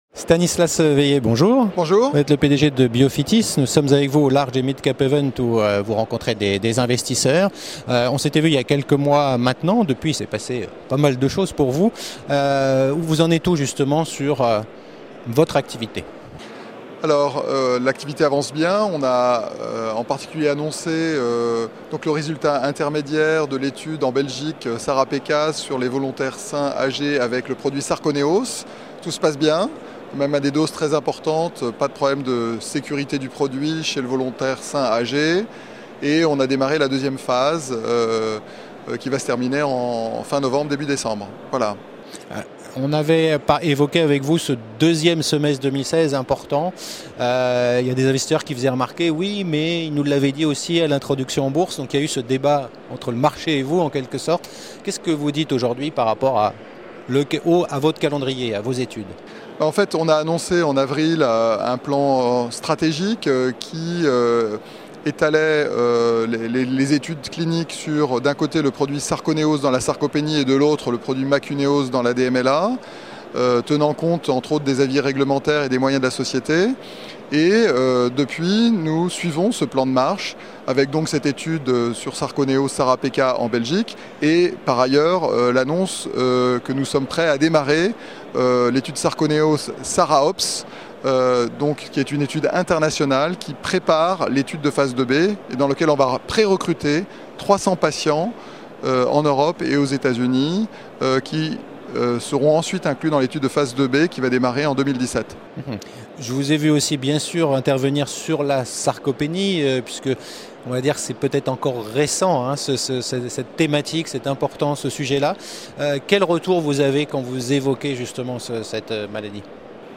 La Web TV au Midcap Event 2016 organisé par CF&B au Palais Brongniart